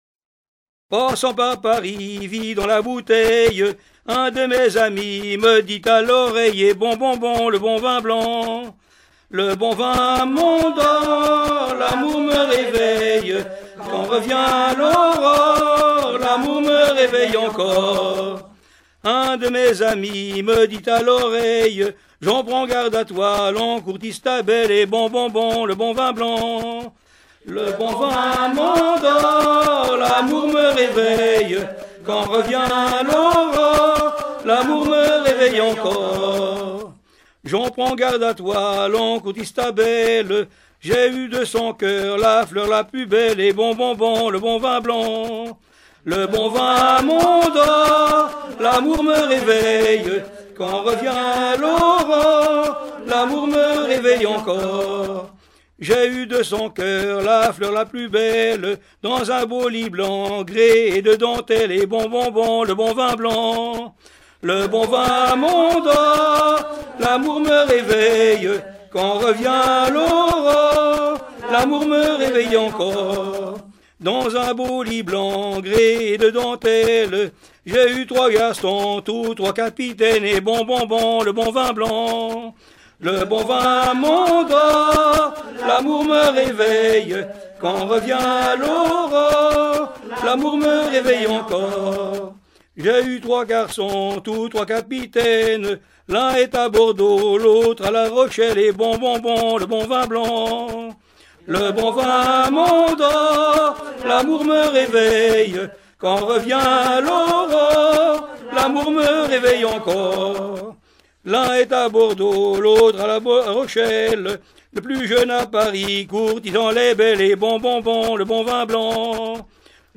Genre laisse
Fonds Arexcpo en Vendée